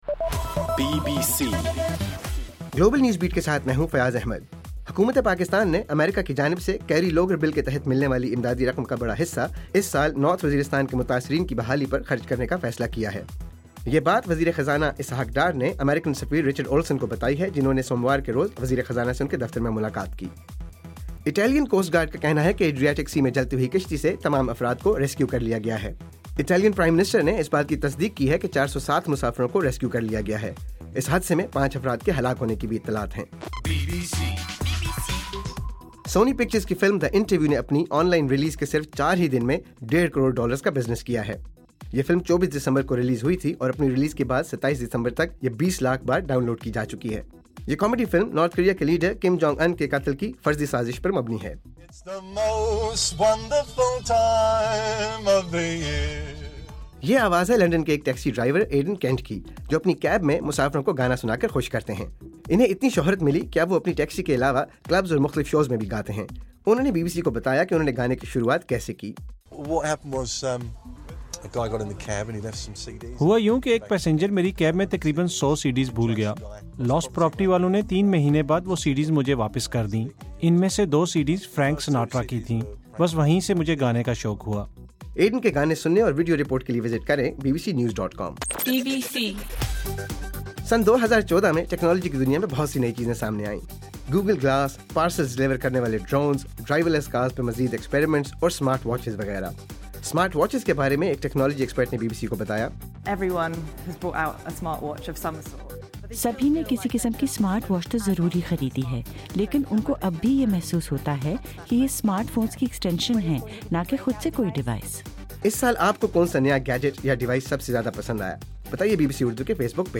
دسمبر 29: رات 8 بجے کا گلوبل نیوز بیٹ بُلیٹن